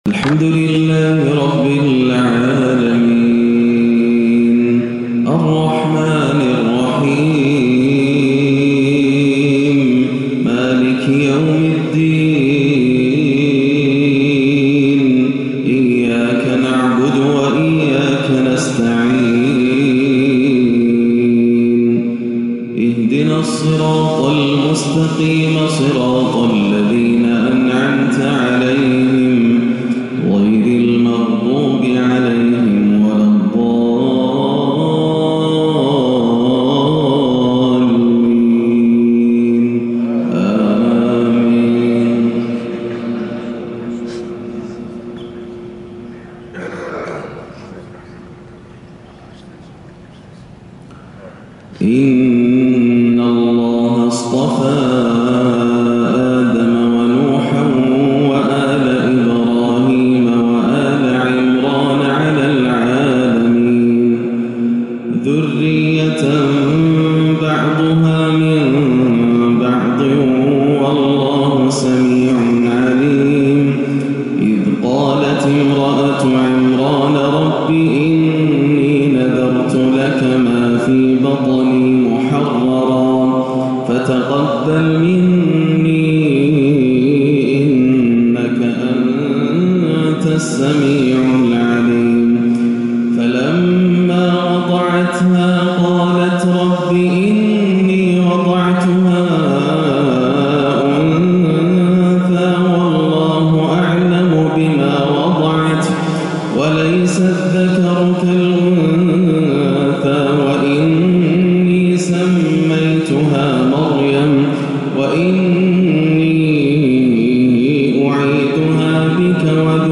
(إن الله يرزق من يشاء بـغـير حساب) فجرية رائعة - الثلاثاء 3-1-1438 > عام 1438 > الفروض - تلاوات ياسر الدوسري